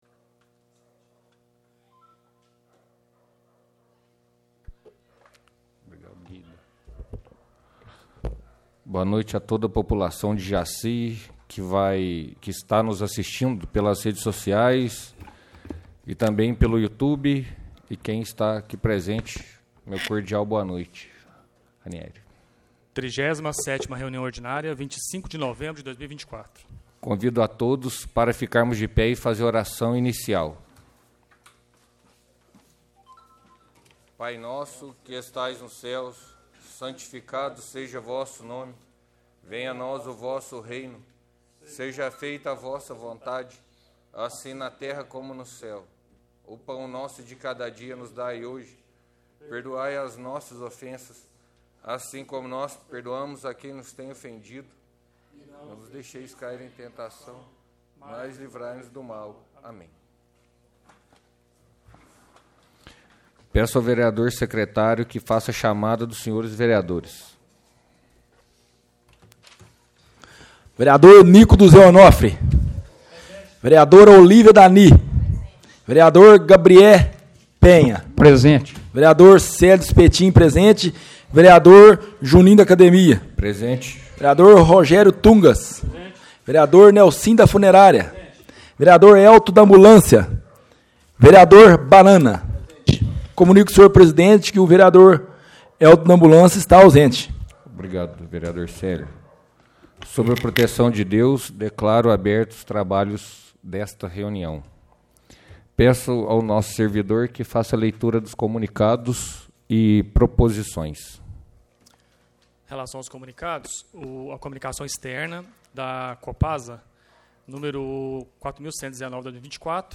reuniaocamara